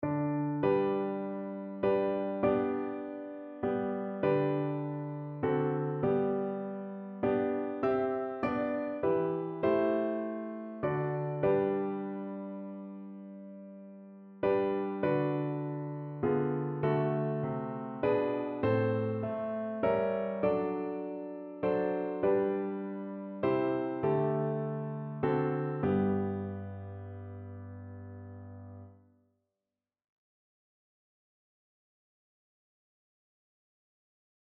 Notensatz 2 (4 Stimmen gemischt)